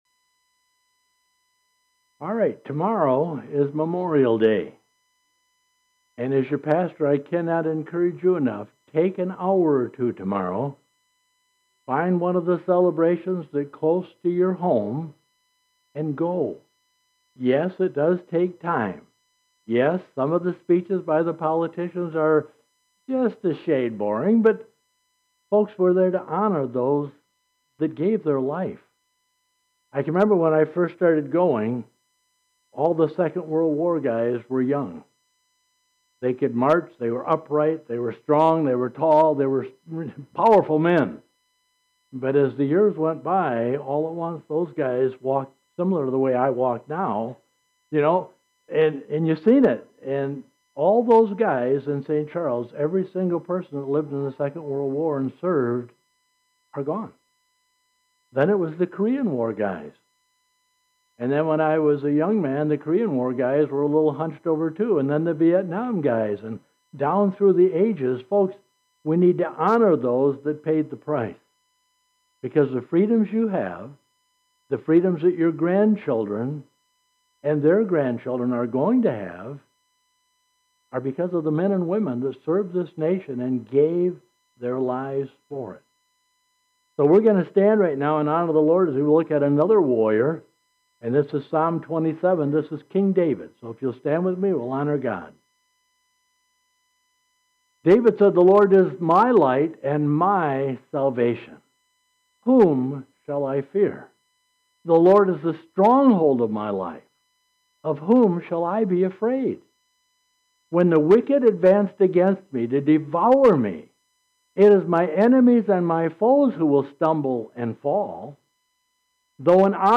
Today, together we will express our rightful appreciation that is due to these men and women It is truly fitting for our Memorial Day service to hear from a warrior of past years, King David.